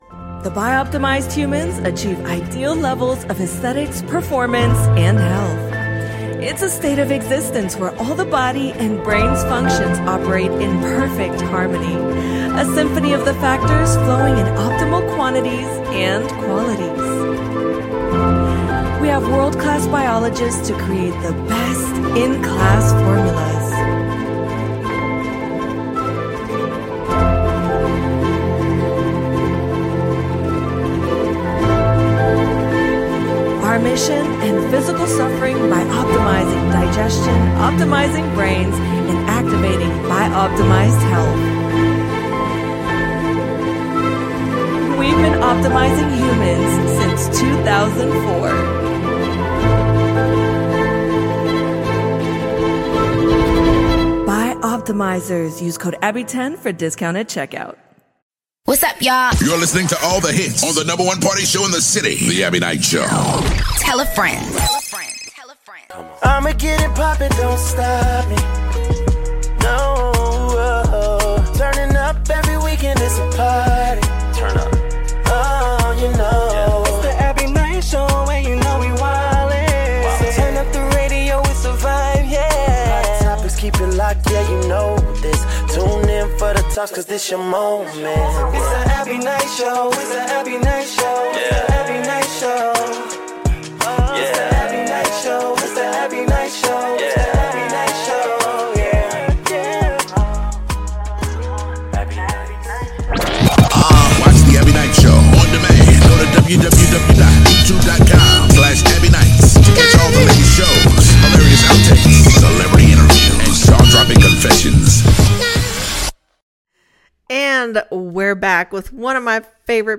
Real talk.